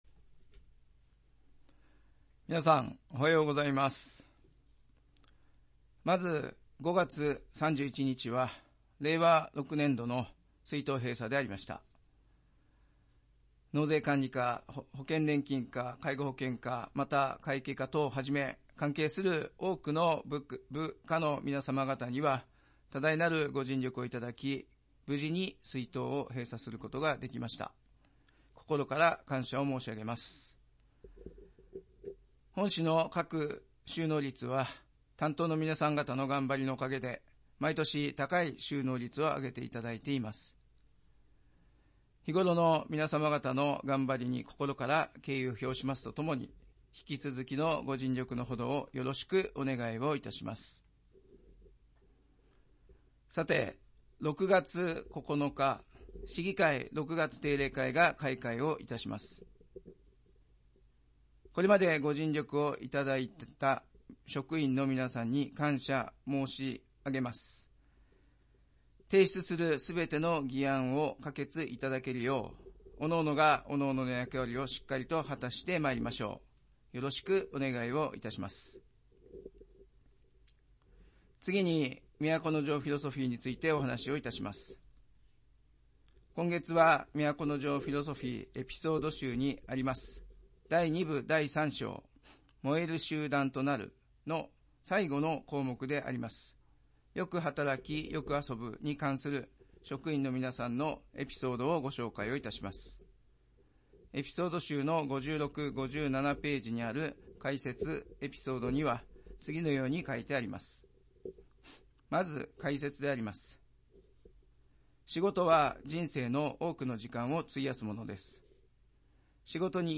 市長が毎月初めに行う職員向けの庁内メッセージを掲載します。